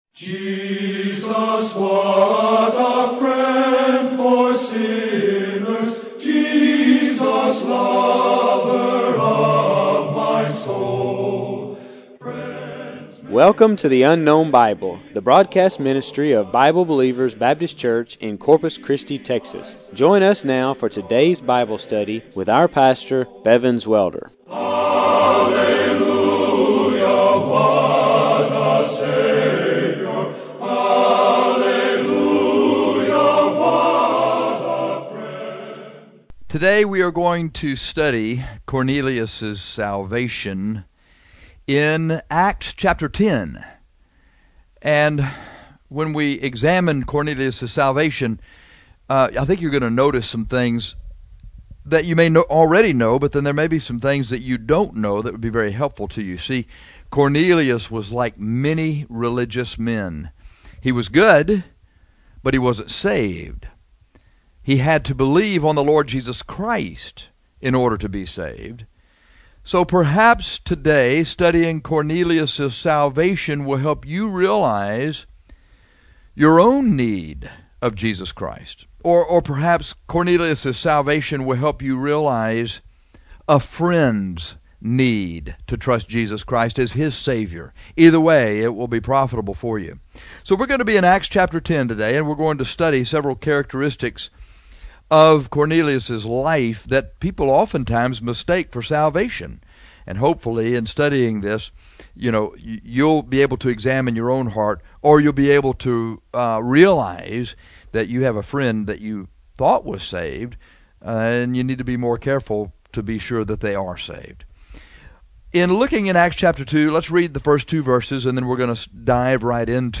This sermon examines Cornelius salvation.